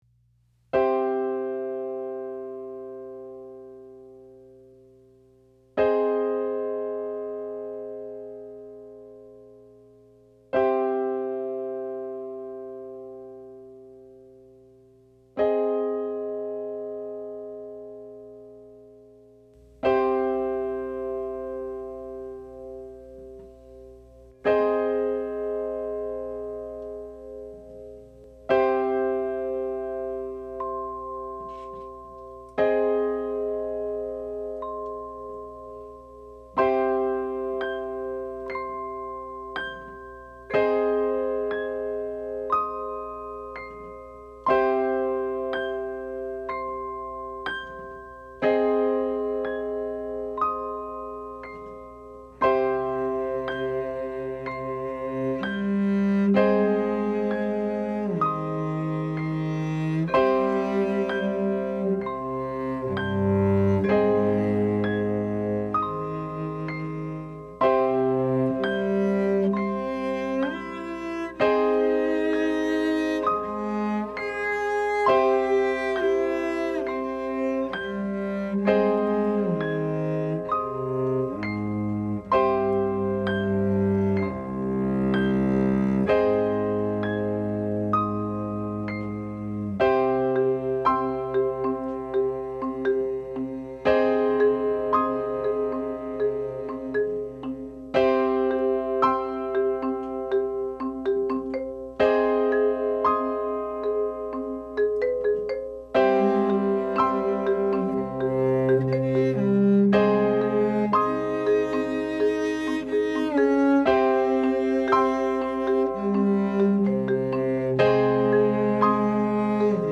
for cello, marimba/vibraphone, and piano